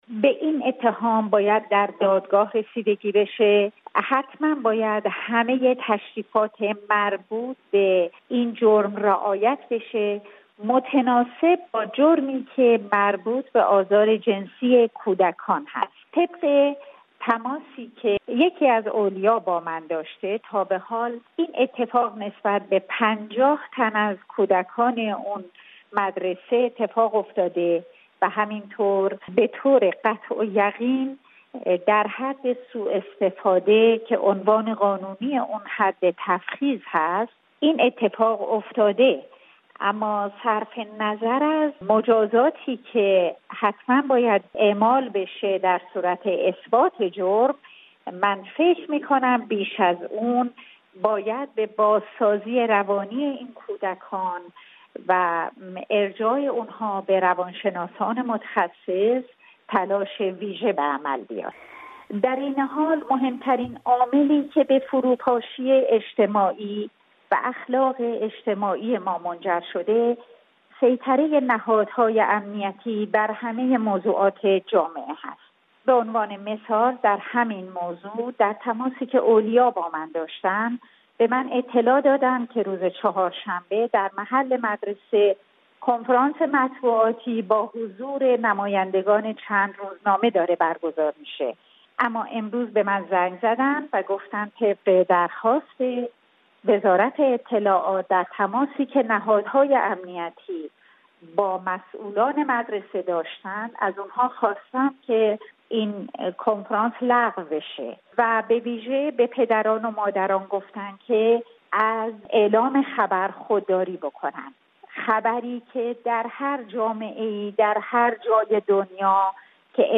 منابع مطلع از ثبت شکایت خانواده‌های دوازده تن از دانش آموزانی که در یک مدرسه غیر انتفاعی مورد آزار جنسی قرار گرفته اند ، خبر داده اند. رادیو فردا در گفت و گو با نسرین ستوده،‌ وکیل دادگستری در تهران ابتدا از او در مورد عنوان اتهامی این جرم پرسیده است: